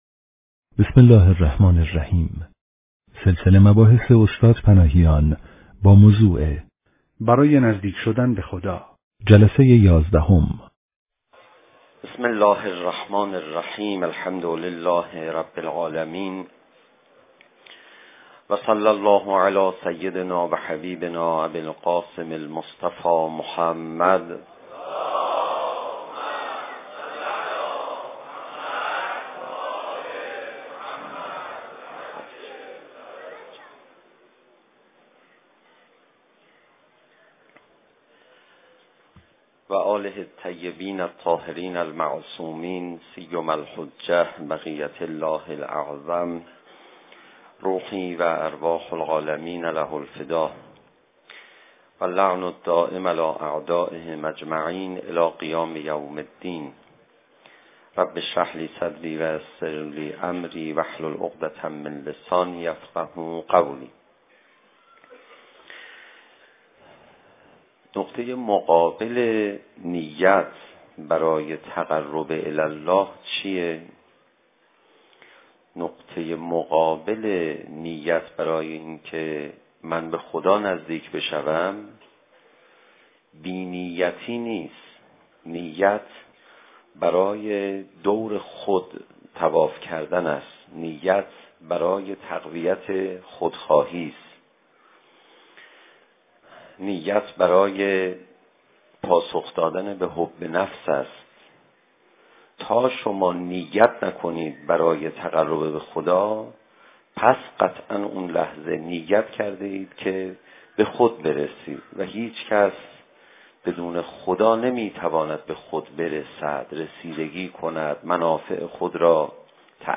صوت/ سخنرانی
در دانشگاه امام صادق(ع) دهه اول ماه محرم ـ سال 94 با موضوع "برای نزدیک شدن به خدا" جلسه یازدهم.